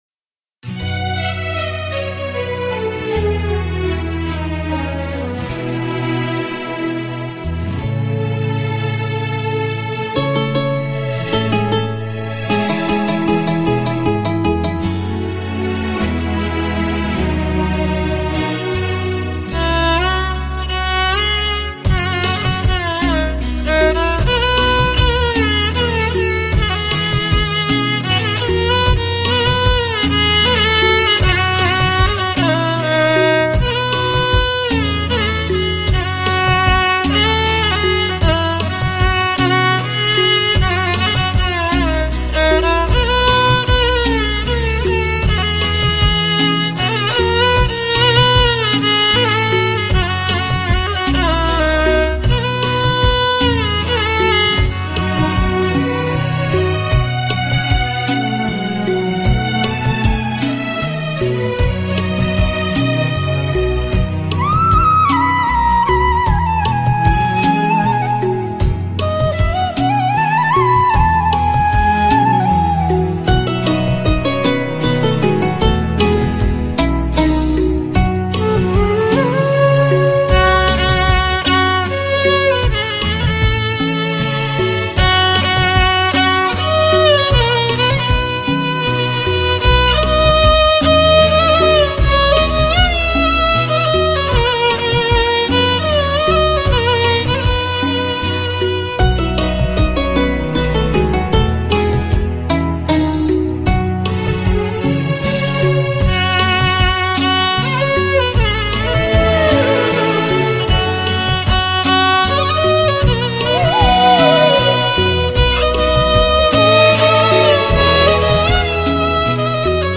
Instrumental Songs > Old Bollywood